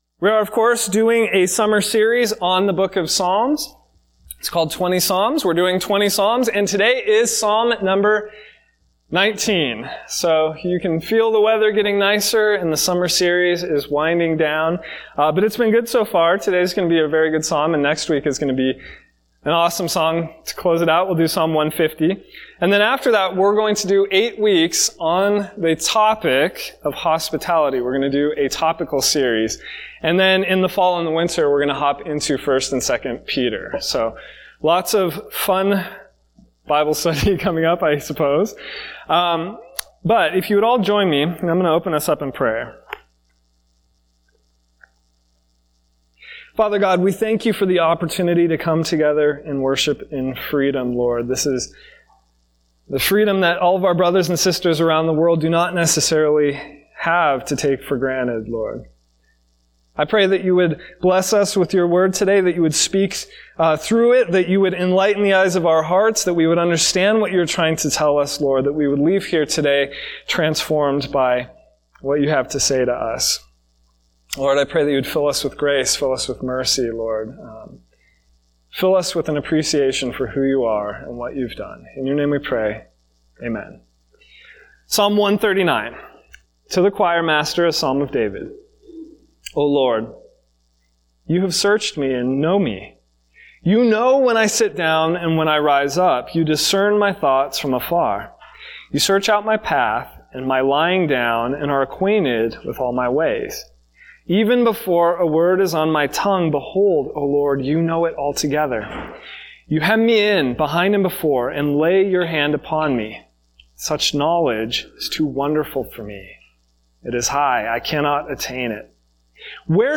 Sermons by Series | Sandy Ridge Church